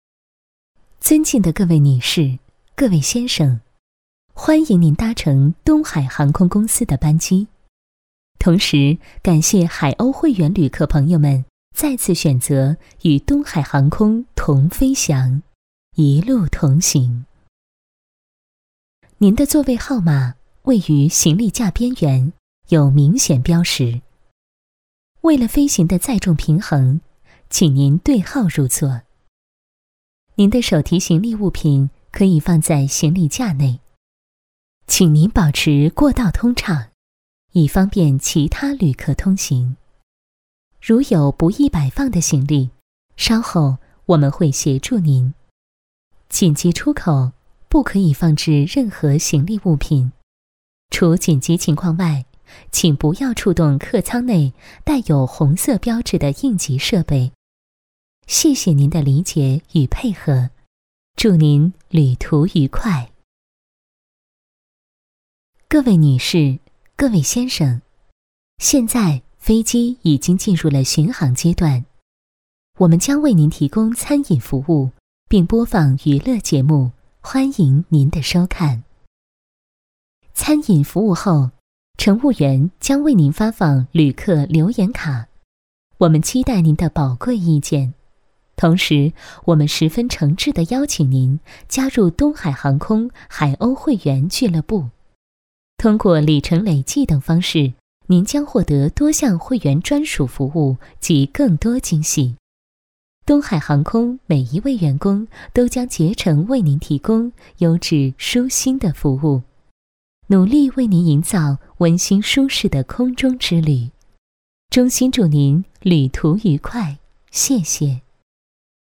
语言：普通话 （44女）
特点：大气浑厚 稳重磁性 激情力度 成熟厚重
风格:大气配音
温馨广播--东海航空公司广播词.mp3